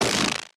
tesla-turret-beam-deflection-4.ogg